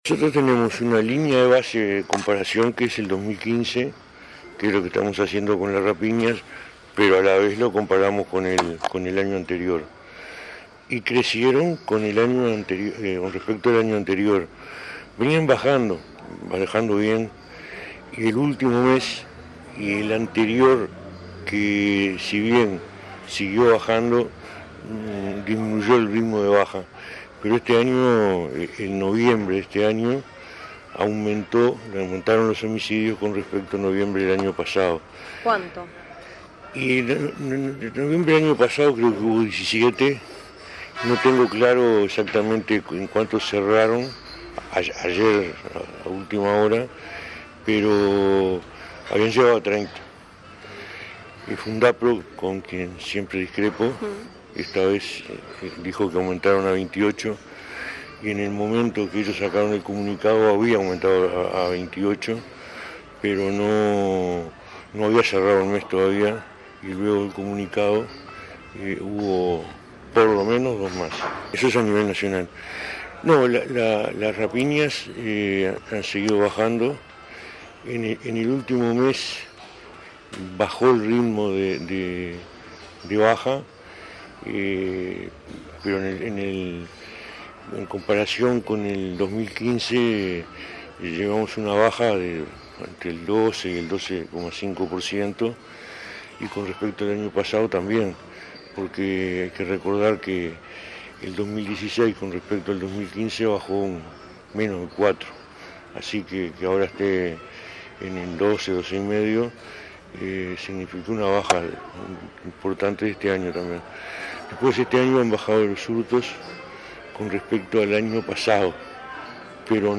“La Policía tiene más del 60 % de esclarecimientos de homicidios por año, guarismo superior al del FBI y bastante por encima de los países de la región”, aseguró a la prensa el ministro del Interior, Eduardo Bonomi, quien apuntó que en noviembre de 2017 aumentaron esos delitos en comparación al mismo mes de 2016. En el caso de las rapiñas y hurtos, siguen en descenso, subrayó.